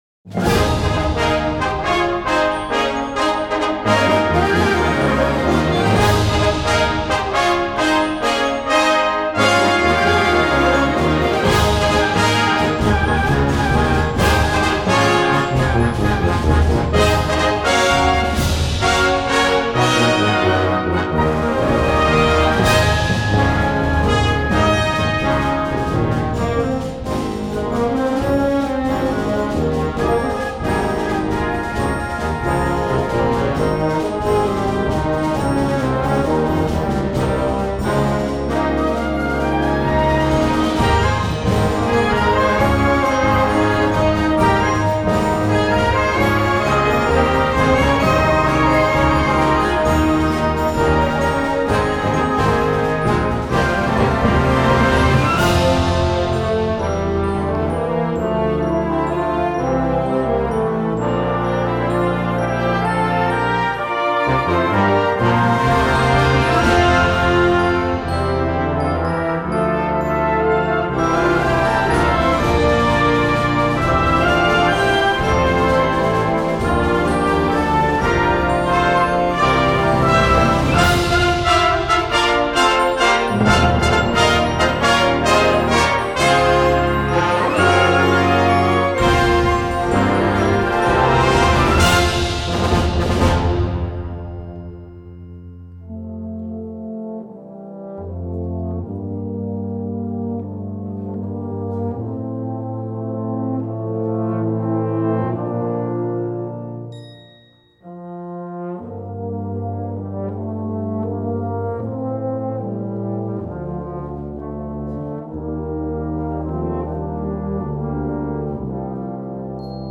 Gattung: Konzertwerk für Blasorchester
Besetzung: Blasorchester
einem Scherzo voller Sturm und Temperament